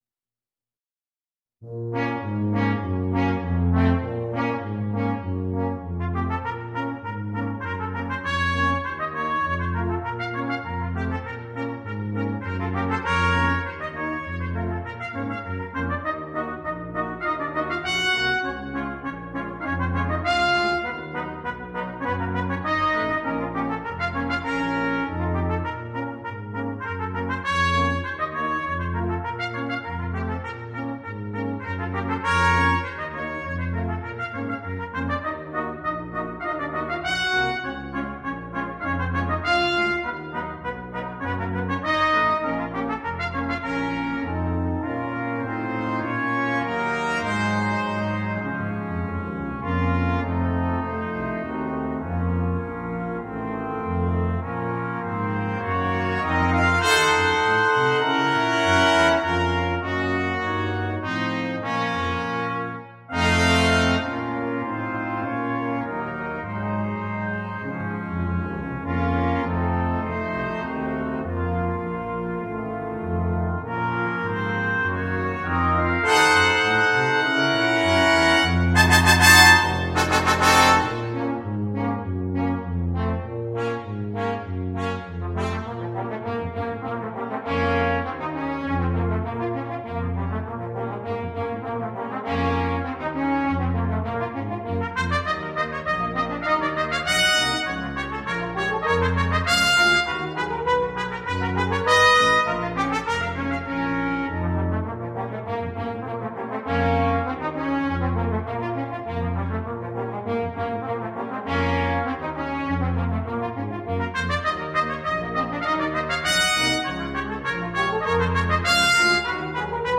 для брасс-квинтета